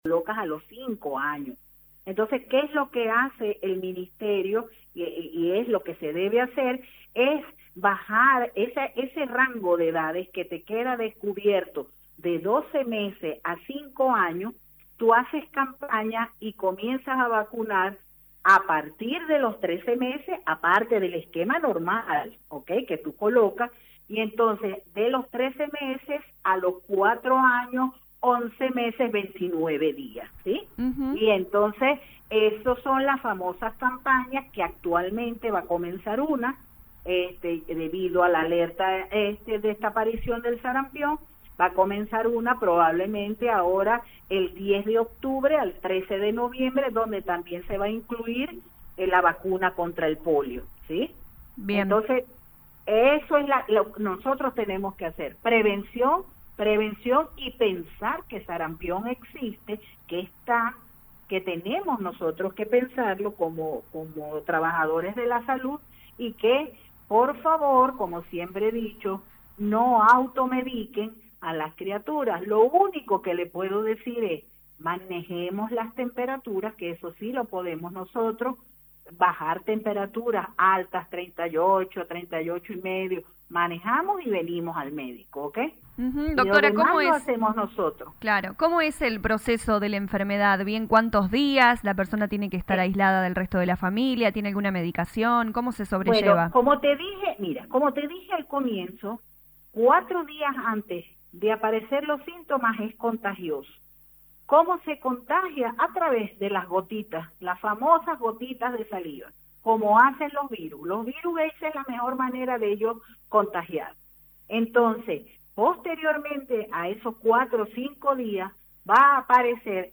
En diálogo con LA RADIO 102.9 FM la médica pediatra